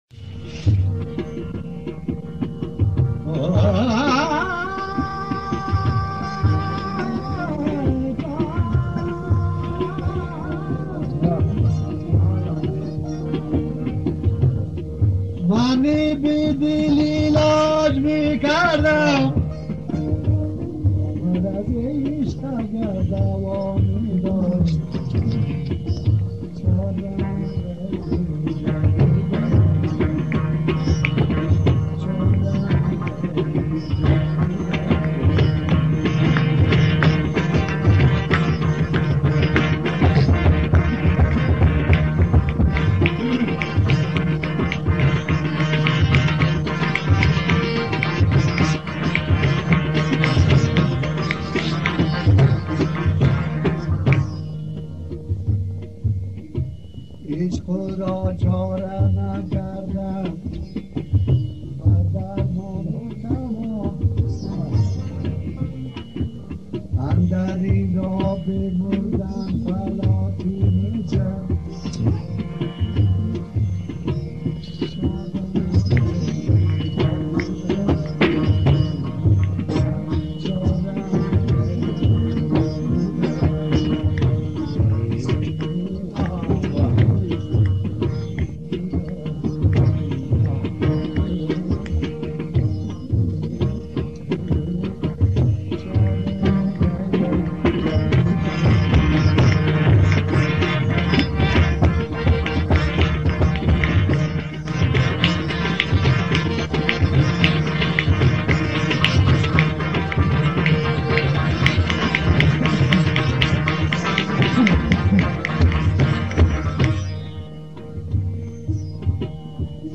(آهنگ از ابتدا ناقص است)